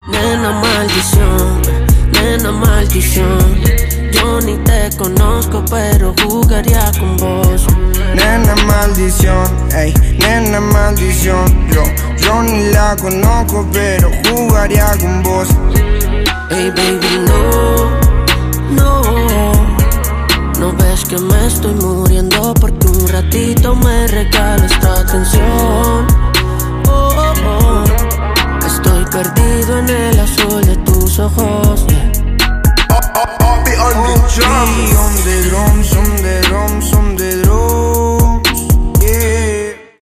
поп , романтические
рэп , хип-хоп